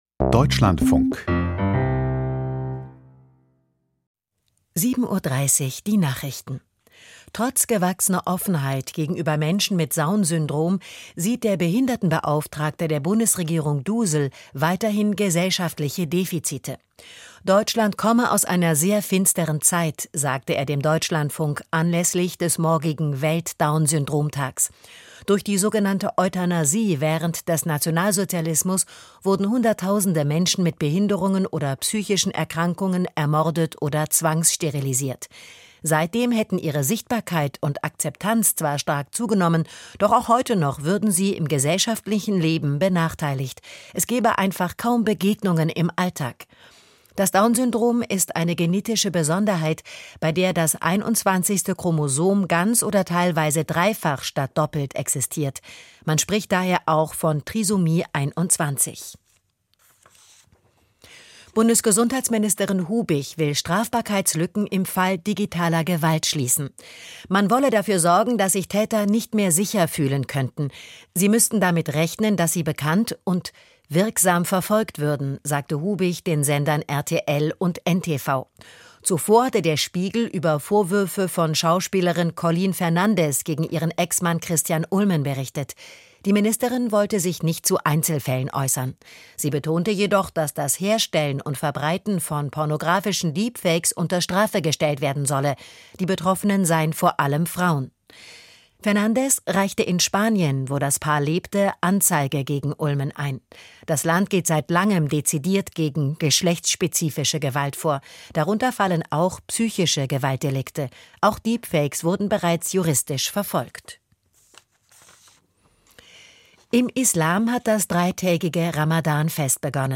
Die Nachrichten vom 20.03.2026, 07:30 Uhr